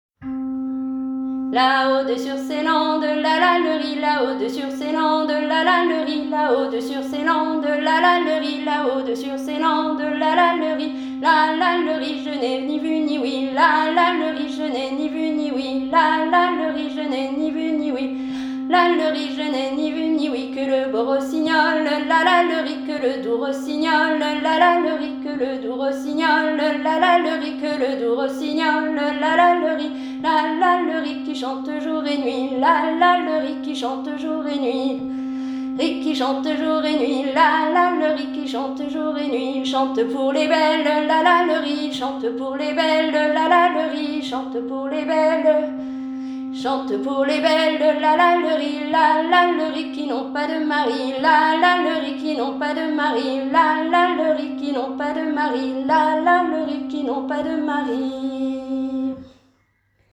Centre Breton d’Art Populaire - Musiques traditionnelles vivantes
ridee_la_haut_dessur_ces_landes_en_do.mp3